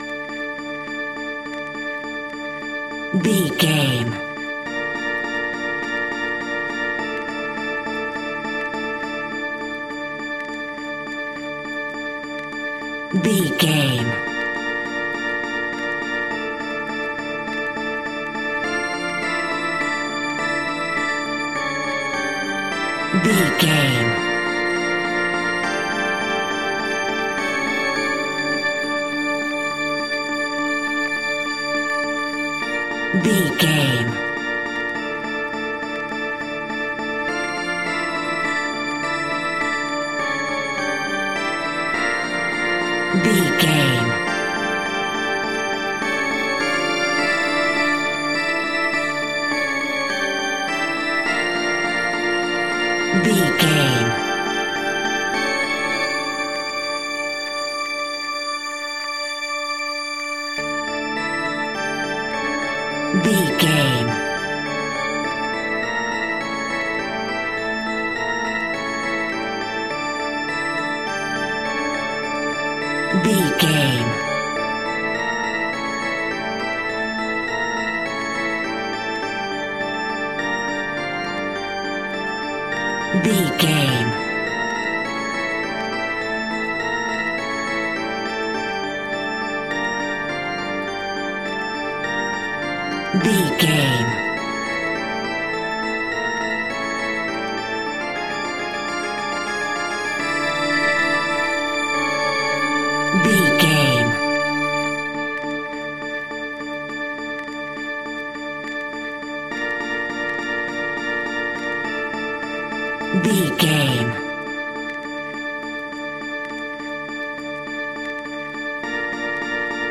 Aeolian/Minor
ominous
dark
haunting
eerie
electric organ
synth
ambience
pads